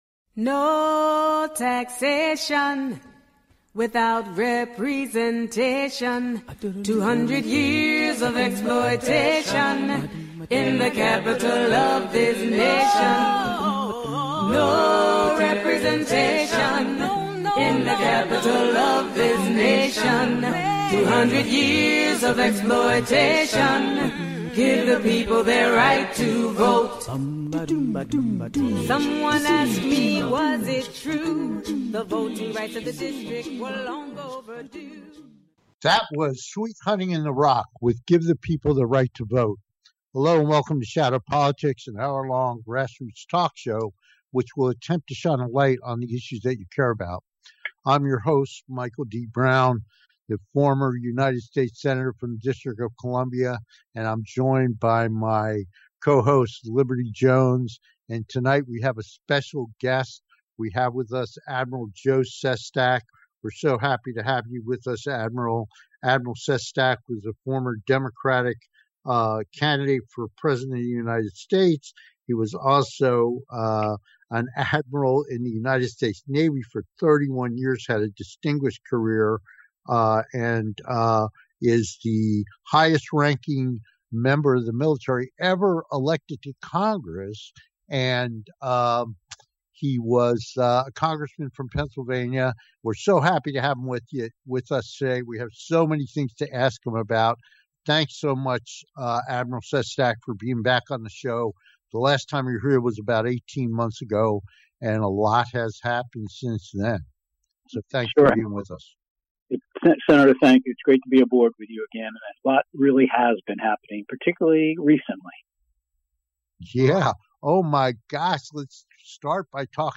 Talk Show Episode
With Guest, Admiral Joe Sestak - American Politician and Retired U.S. Navy Officer